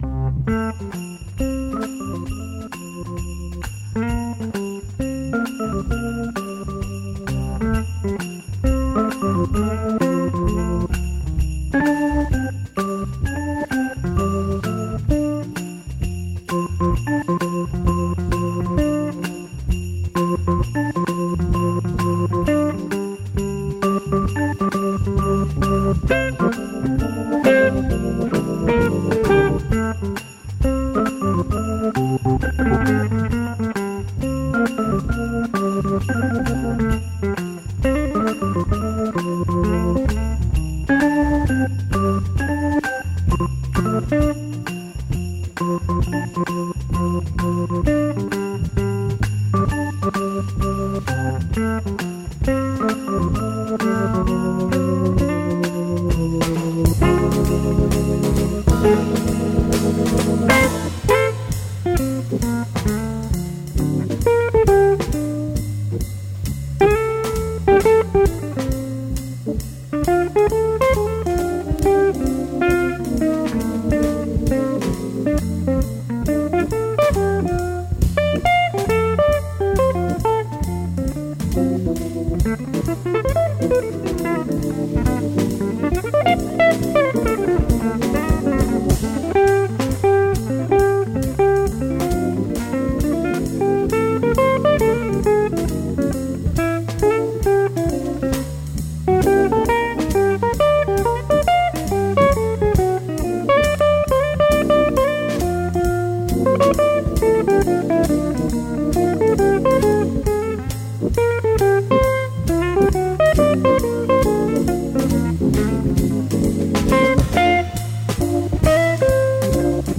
cookin’ up a soulful Christmas .